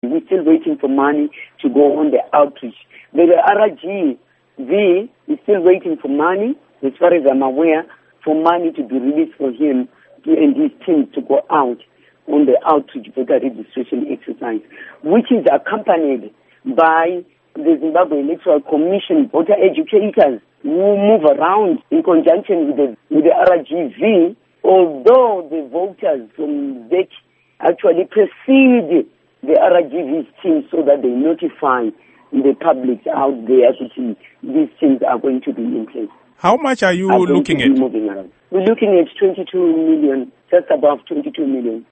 Interview With Joyce Kazembe